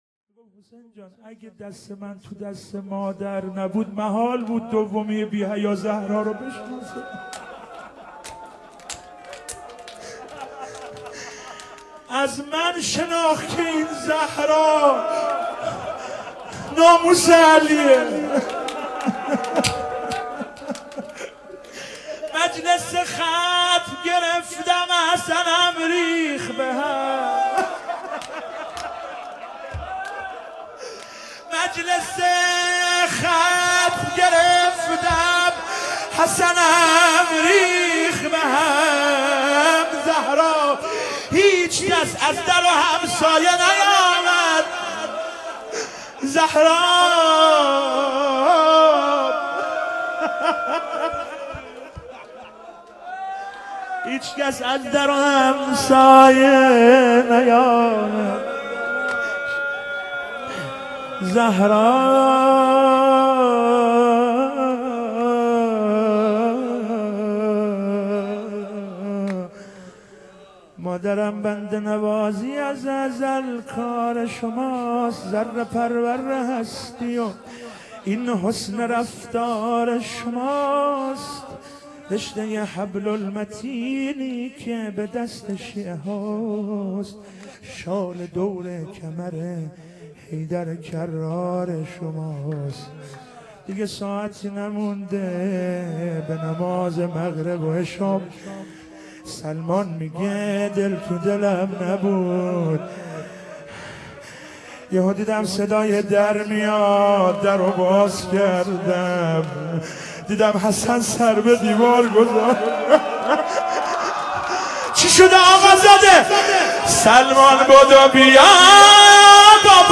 ایام فاطمیه 95 - هیئت محبان الائمه مشهد - روضه